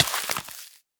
Minecraft Version Minecraft Version 1.21.5 Latest Release | Latest Snapshot 1.21.5 / assets / minecraft / sounds / entity / player / hurt / freeze_hurt1.ogg Compare With Compare With Latest Release | Latest Snapshot
freeze_hurt1.ogg